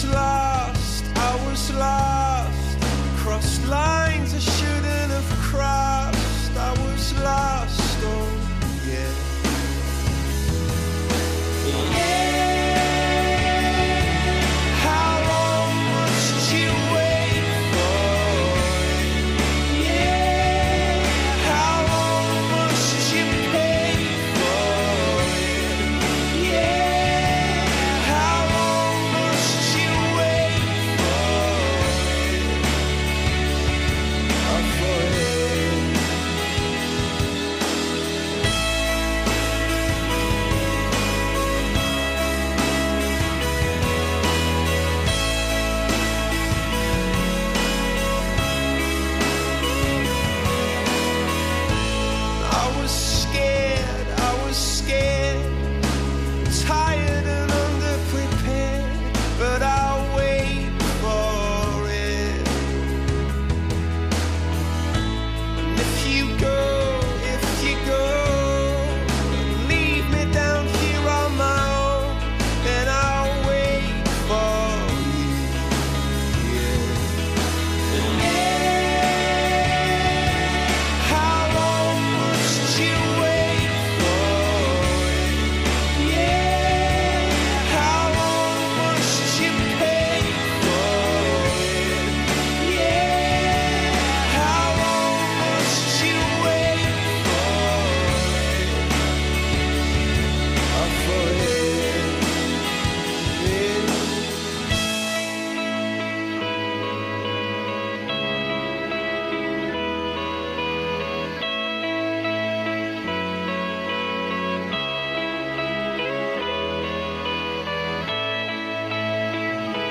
En comunicación con nuestros estudios